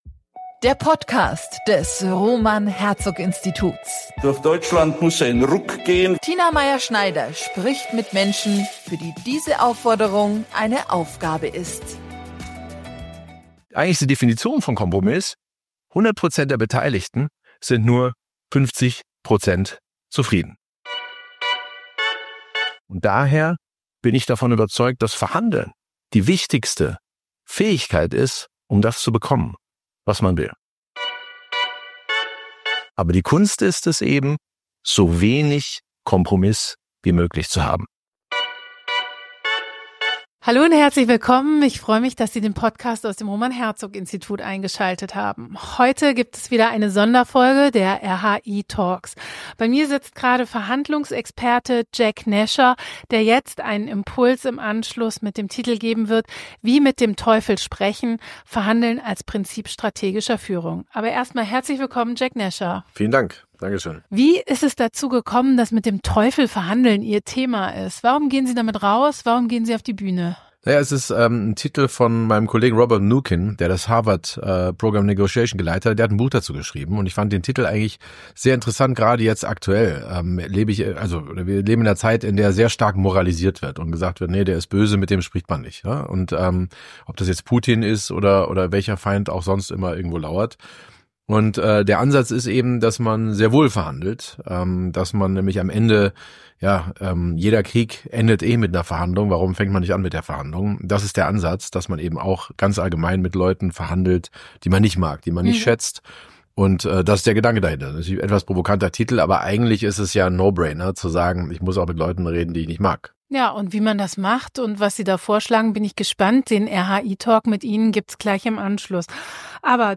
Was macht gute strategische Führung in Politik und Gesellschaft aus? Dieser Frage haben wir uns gemeinsam mit neun Expertinnen und Experten im Rahmen des RHI-Symposiums am 26.11.2024 gewidmet.